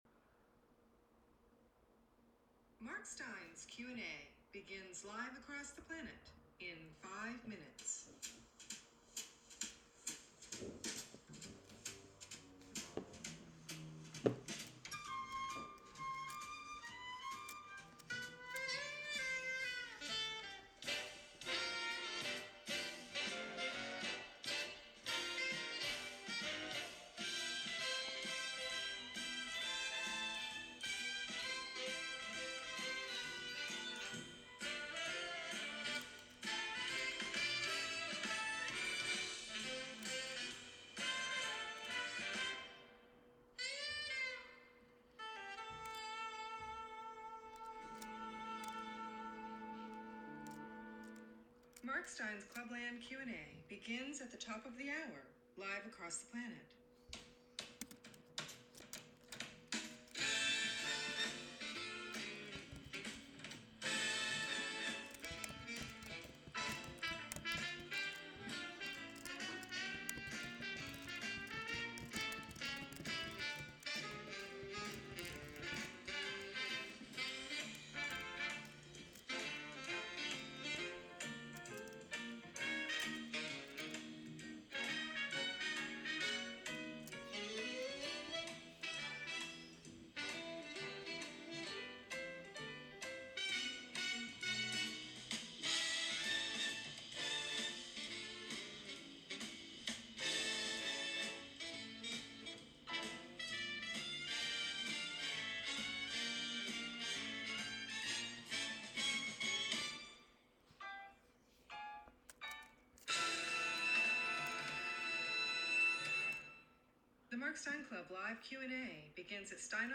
If you missed the live show, you can listen to the replay here.